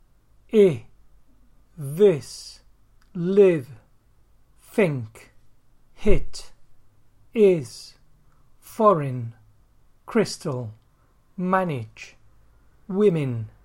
ɪ
this, live, think, hit, is, foreign, crystal, manage, women
ɪ.mp3